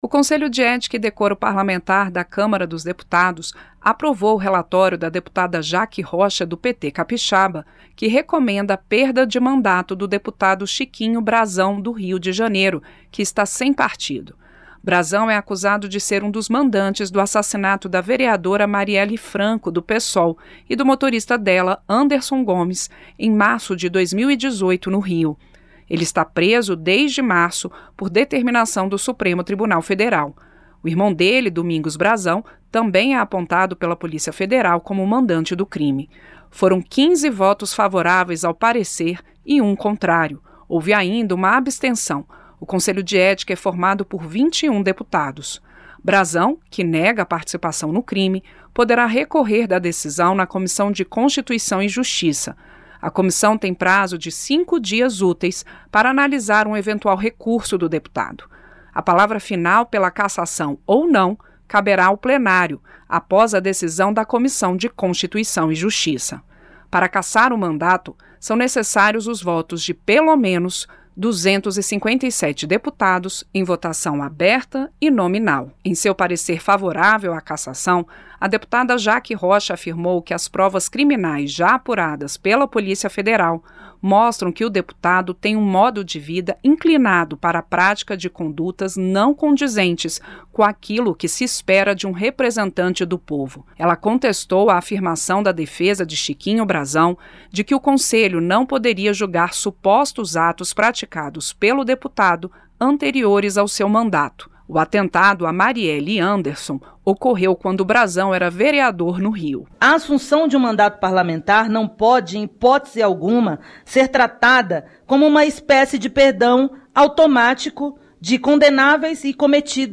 DEPUTADOS DECIDEM PELA CASSAÇÃO DO DEPUTADO CHIQUINHO BRAZÃO, ACUSADO DE TER MANDADO MATAR A VEREADORA MARIELLE FRANCO. CHIQUINHO BRAZÃO NEGA A ACUSAÇÃO E AINDA PODERÁ RECORRER DA DECISÃO. A REPÓRTER